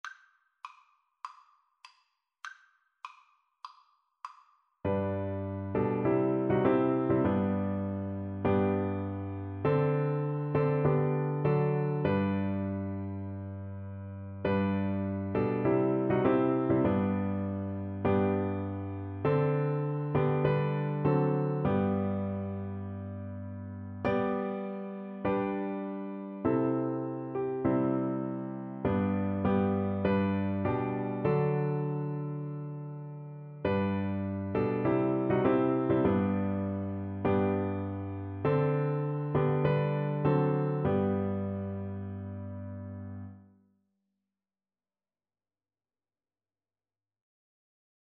Play (or use space bar on your keyboard) Pause Music Playalong - Piano Accompaniment Playalong Band Accompaniment not yet available transpose reset tempo print settings full screen
G major (Sounding Pitch) E major (Alto Saxophone in Eb) (View more G major Music for Saxophone )
4/4 (View more 4/4 Music)
Classical (View more Classical Saxophone Music)